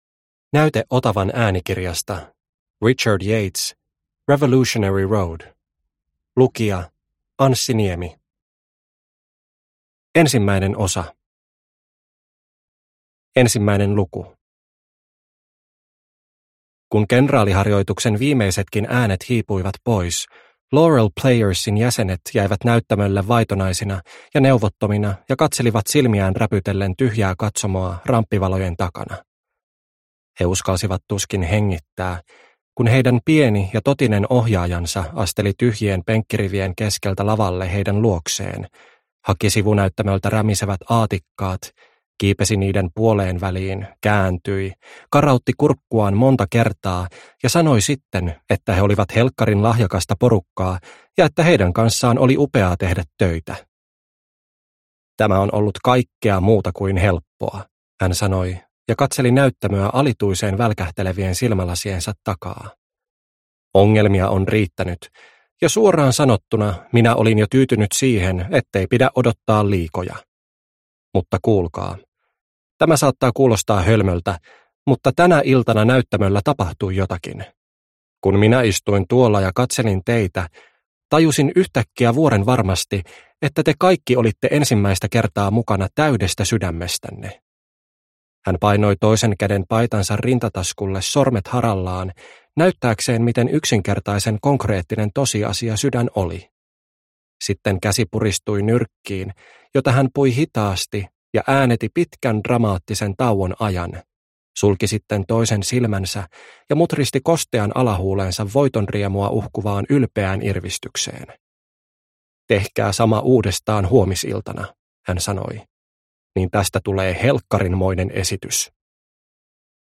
Revolutionary Road – Ljudbok – Laddas ner